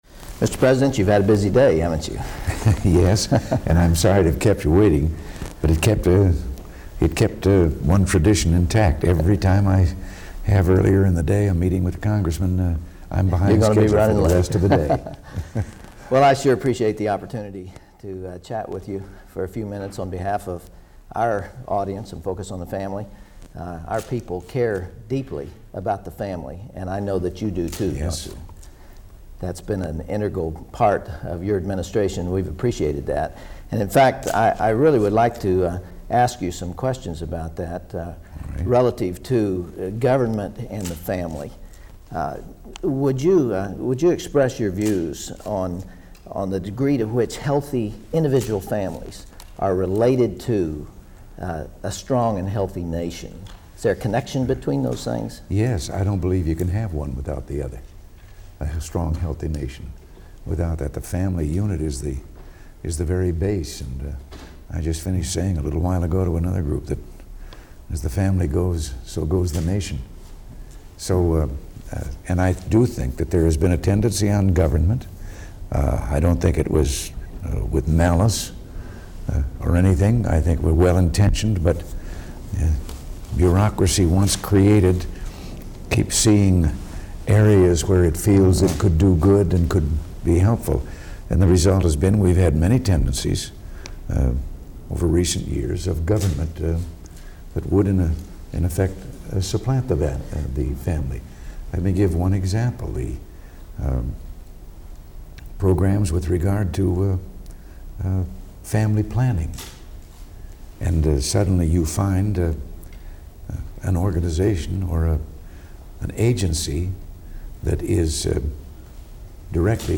Dr. James Dobson: White House Interview With President Ronald Reagan (transcript-audio-video)
jamesdobsoninterviewronaldreagan.mp3